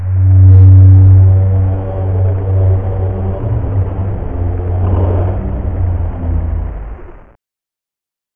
RobotWhaleScream-006.wav